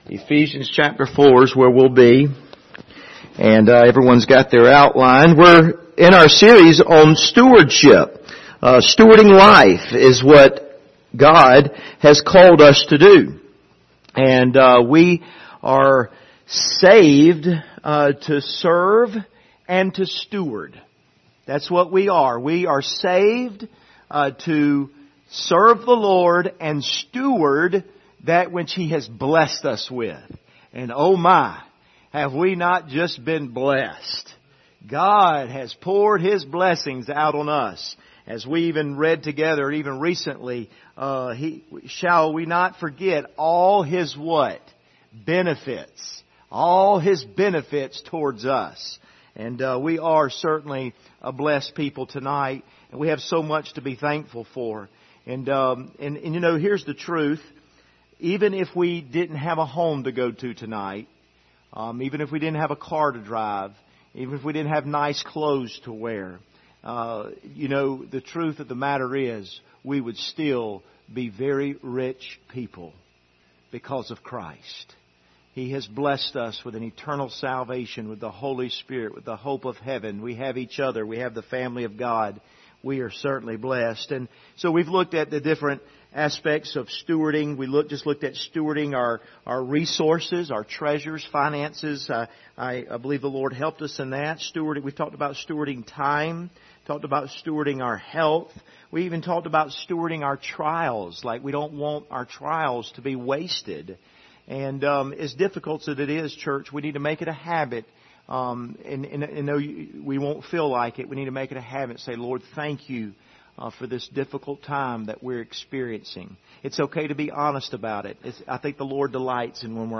Stewarding Life Service Type: Wednesday Evening Topics